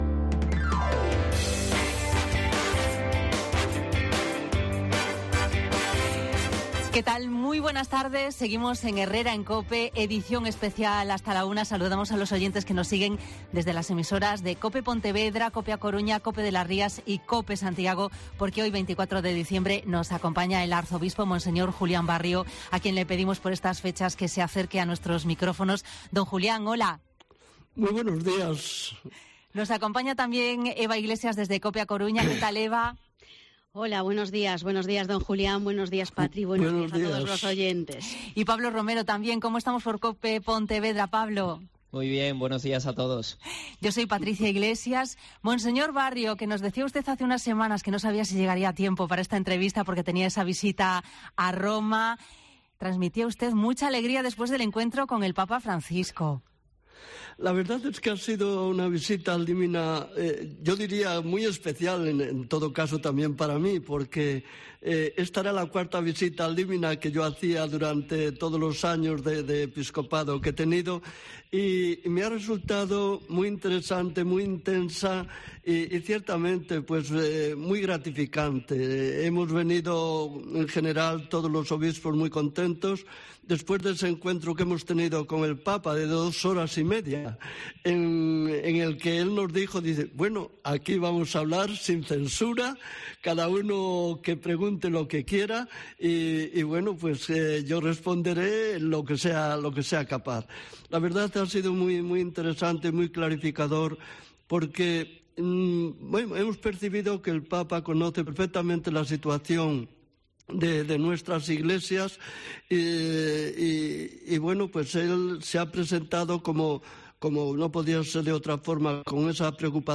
Programa especial con el Arzobispo de Santiago, Monseñor Julián Barrio, con motivo del día de Nochebuena